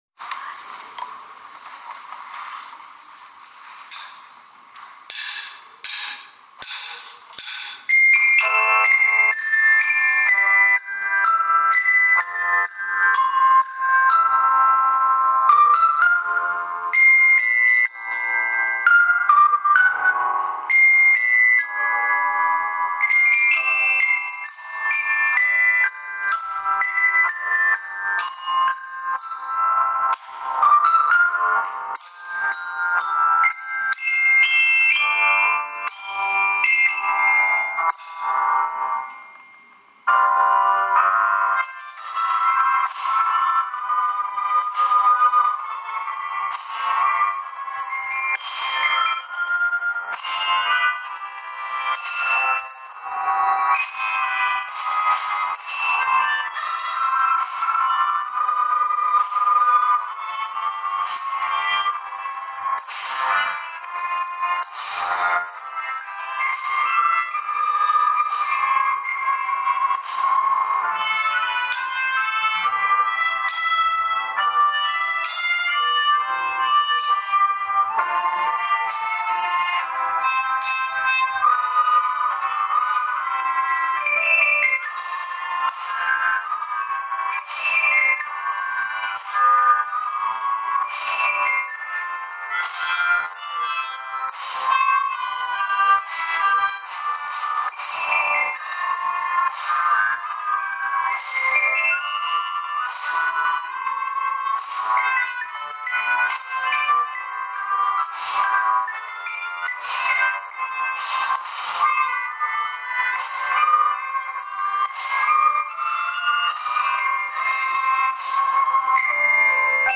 3月1日(金)、5年生が中心になって企画運営をして｢6年生を送る会｣を行いました。
6年生からもメッセージと「Lemon」の合奏の発表がありました。